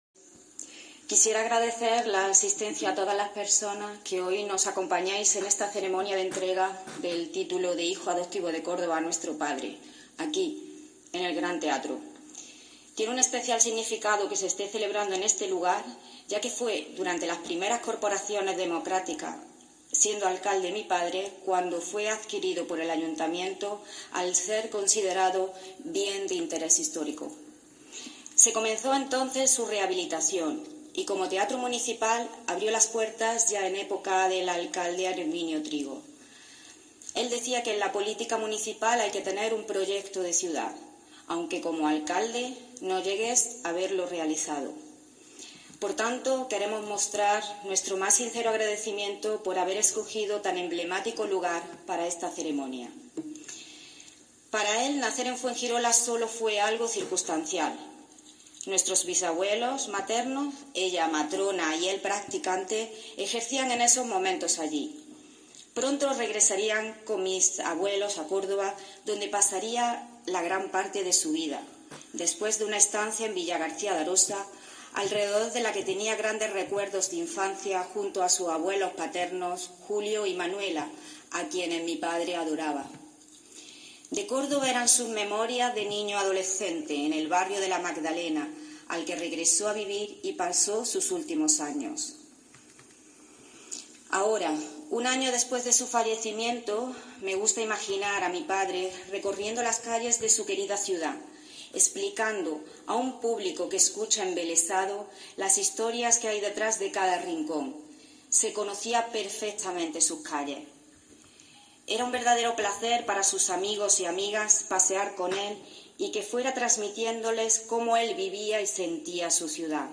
Representantes institucionales, políticos de todos los partidos y miembros de colectivos sociales arroparon a la familia durante la ceremonia